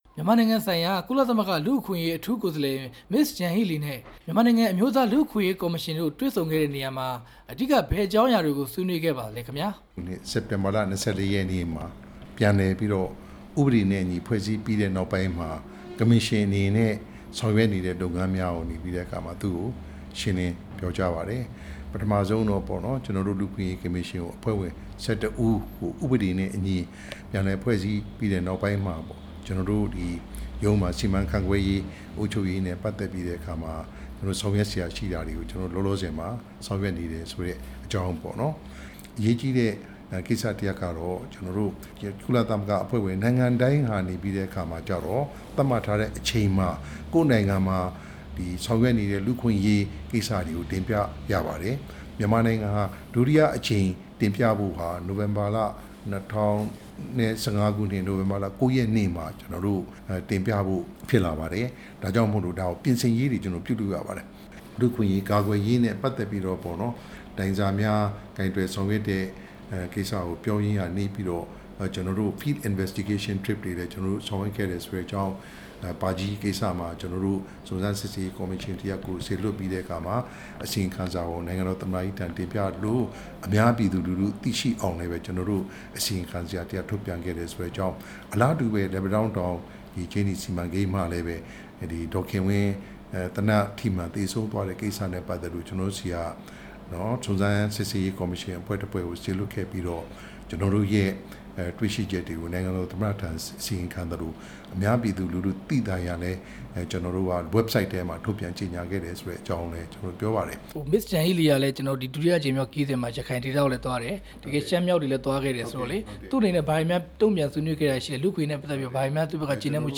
အမျိုးသားလူ့အခွင့်အရေးကော်မရှင် ဥက္ကဋ္ဌ ဦးဝင်းမြနဲ့ မေးမြန်းချက်